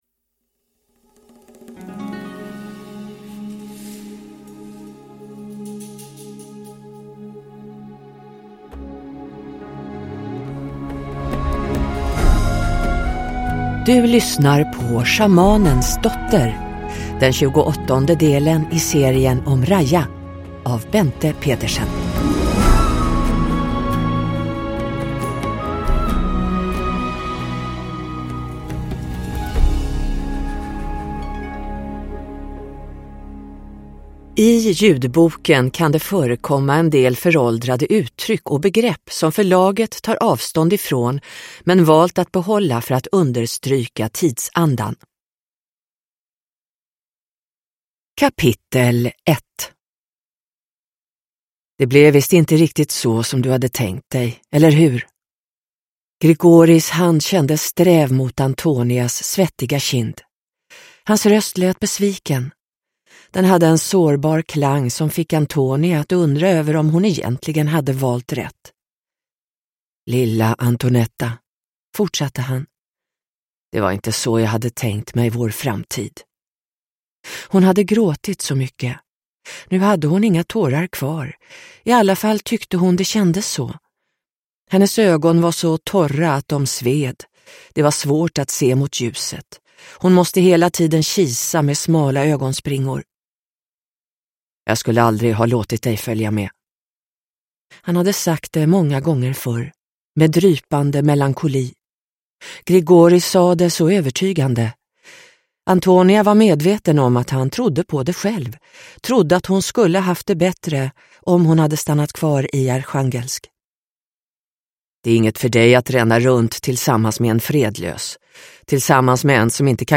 Schamanens dotter – Ljudbok – Laddas ner